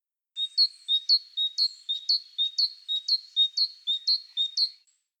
ArtKohlmeise (Parus major)
GesangHauptgesangszeit Mitte Februar bis Anfang Juni, im März am intensivsten, höchste Gesangsaktivitäten in den frühen Morgenstunden
Kohlmeise – Stimme/Gesang:
Kohlmeise-gesang.mp3